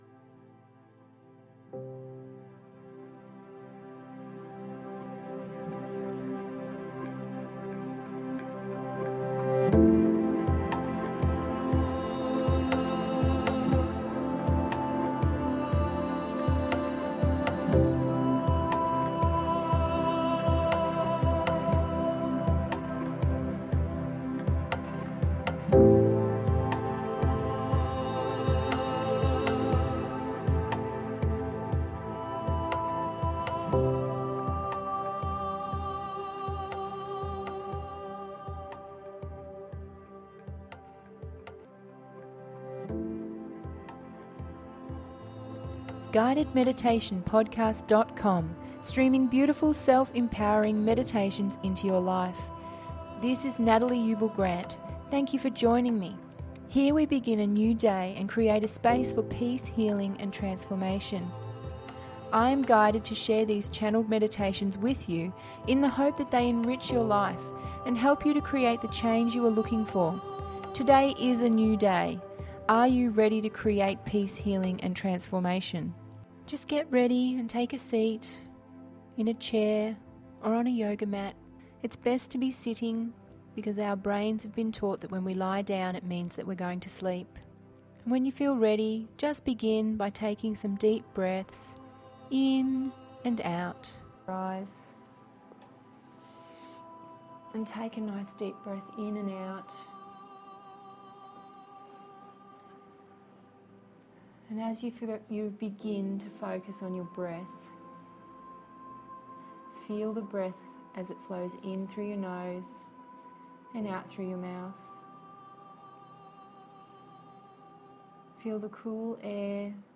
Full moon Totem Pole Emotional Release…025 – GUIDED MEDITATION PODCAST